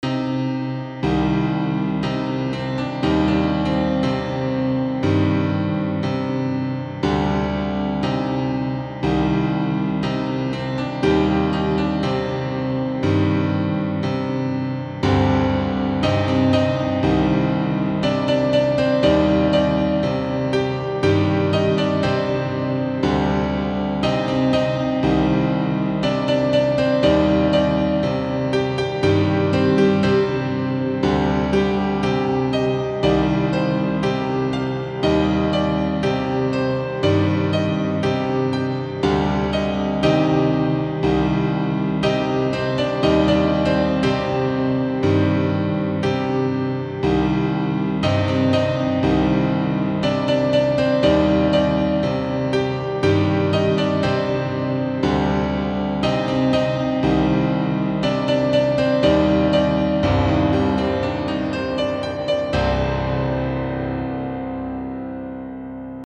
Grey Sky Friday (Midi Record)